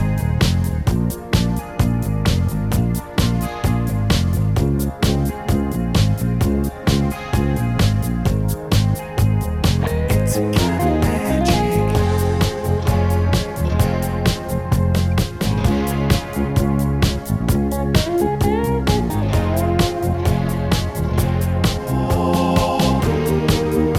One Semitone Down Rock 4:32 Buy £1.50